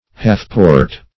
Meaning of half-port. half-port synonyms, pronunciation, spelling and more from Free Dictionary.
Search Result for " half-port" : The Collaborative International Dictionary of English v.0.48: Half-port \Half"-port`\ (-p[=o]rt`), n. (Naut.) One half of a shutter made in two parts for closing a porthole.